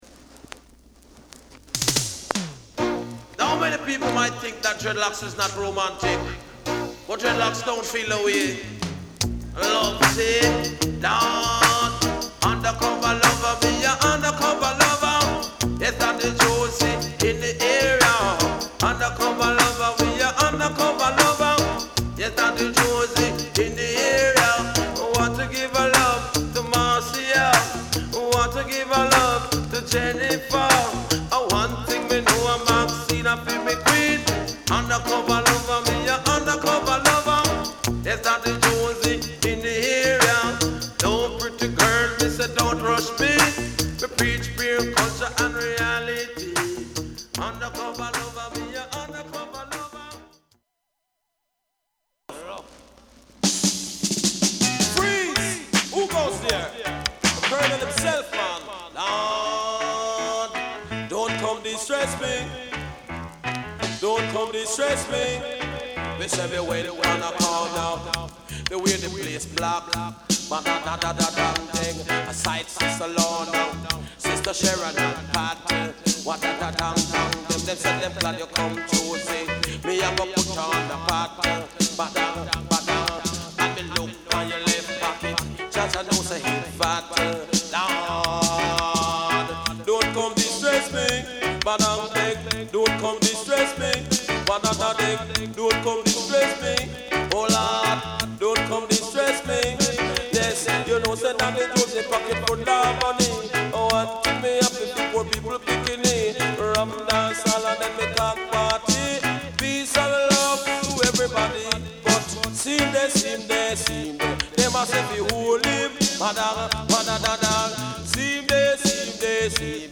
REGGAE / DANCEHALL
プレス・ノイズ有り（JA盤、Reggaeのプロダクション特性とご理解お願い致します）。